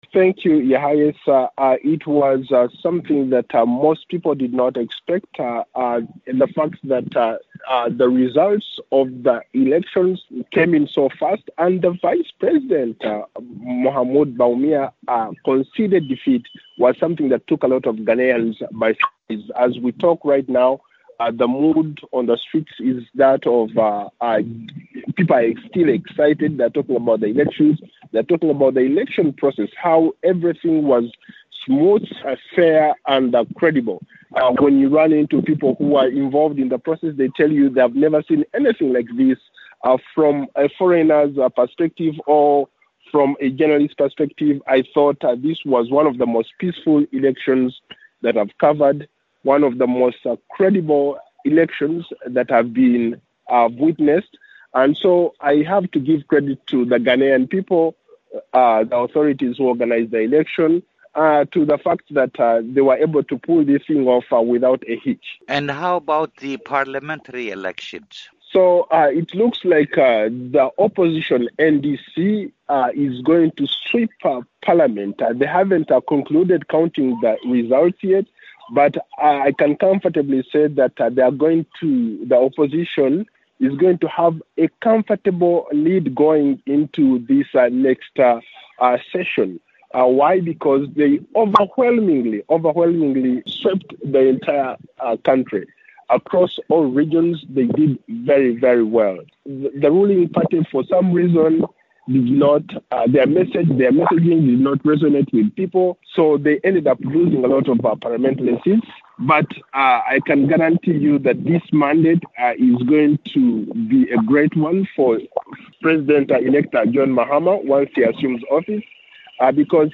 talks to our reporter in Accra